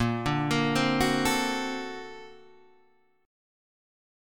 Bb+M9 chord